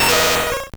Cri de Grotadmorv dans Pokémon Or et Argent.